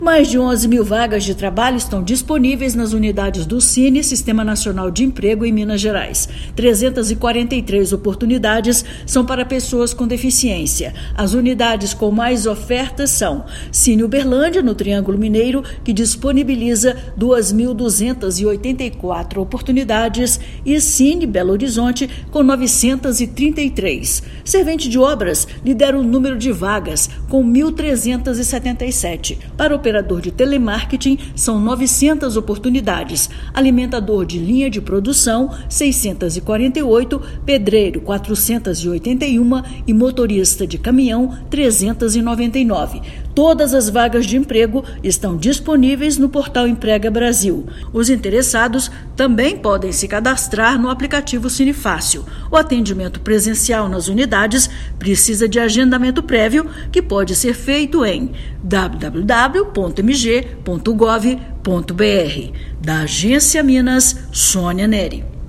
[RÁDIO] Painel do Sine: unidades oferecem mais de 11 mil vagas de trabalho em Minas
Mais de 11 mil vagas de trabalho estão disponíveis em unidades do Sistema Nacional de Emprego (Sine) em Minas Gerais. Ouça matéria de rádio.